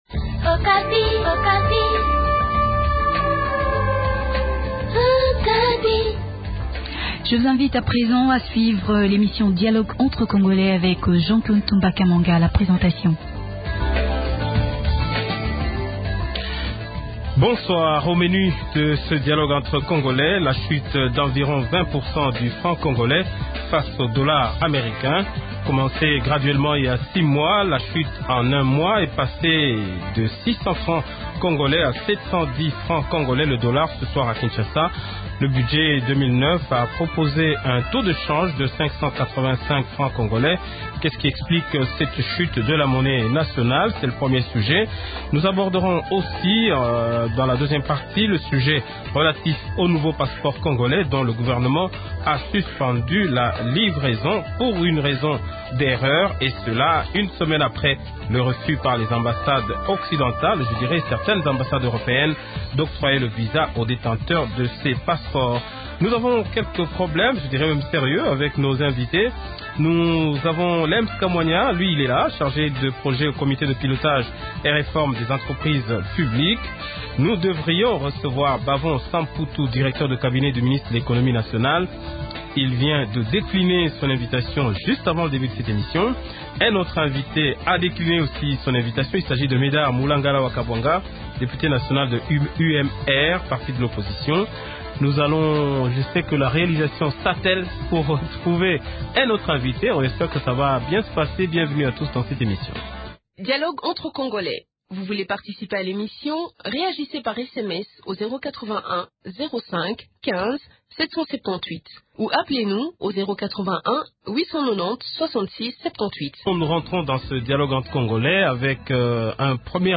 Deux thèmes dans le débat de ce soir à savoir: chute du Franc Congolais et nouveaux passeports congolais refusés par certaines ambassades Européennes